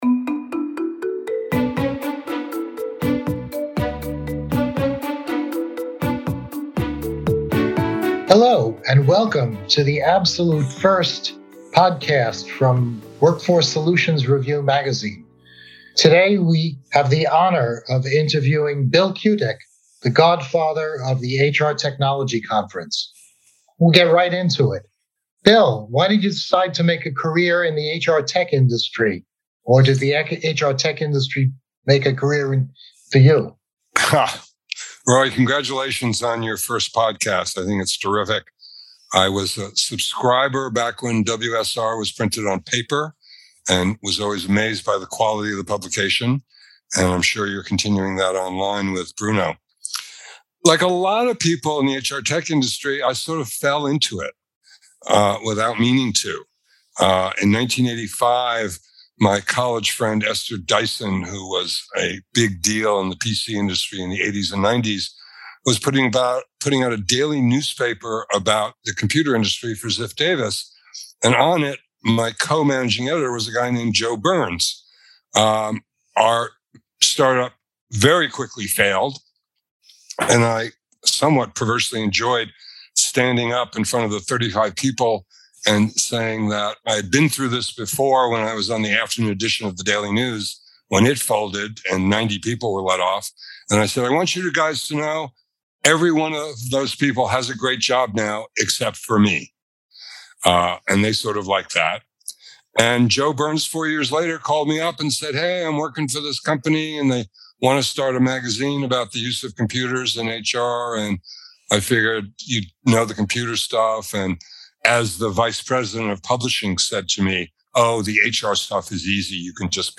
WSR Episode 1 | An Interview